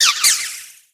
CUTIEFLY.ogg